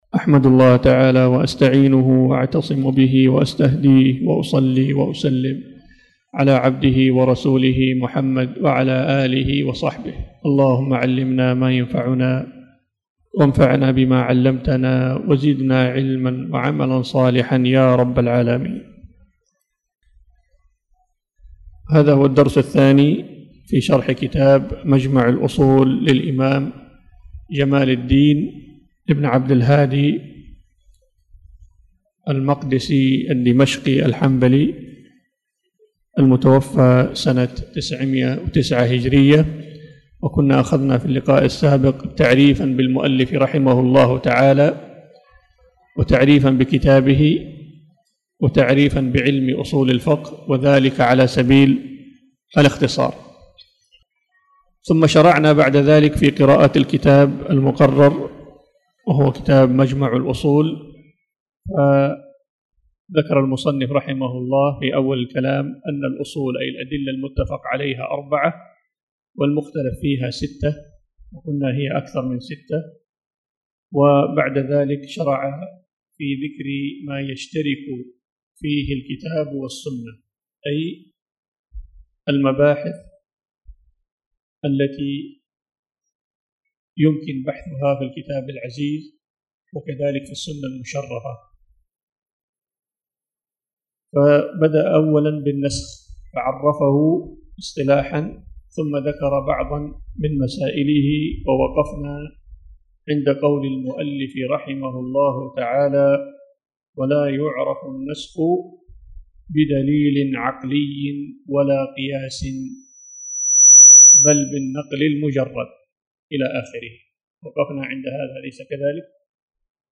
تاريخ النشر ٢ ربيع الأول ١٤٣٨ المكان: المسجد الحرام الشيخ